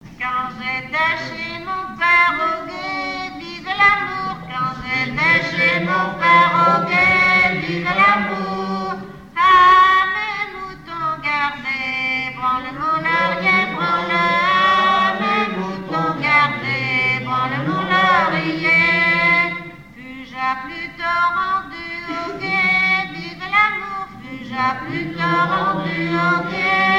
Localisation Saint-Paul-Mont-Penit
Genre laisse
Pièce musicale inédite